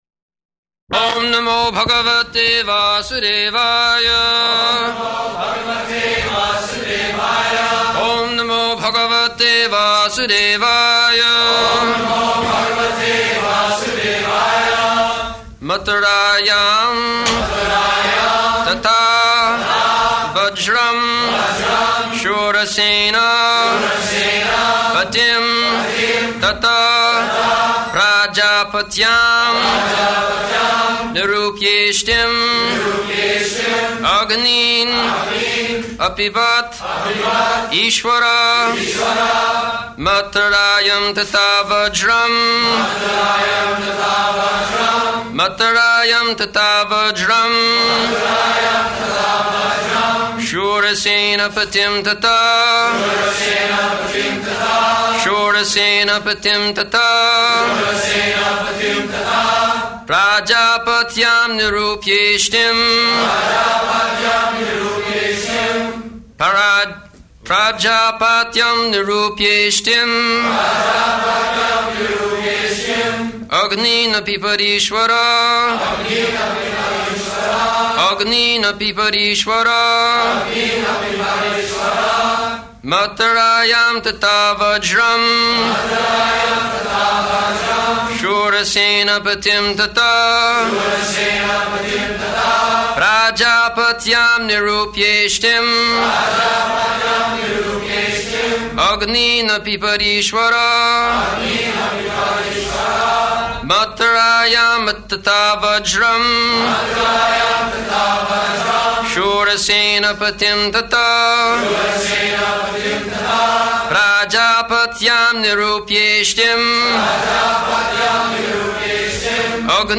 December 17th 1973 Location: Los Angeles Audio file
[Prabhupāda and devotees repeat]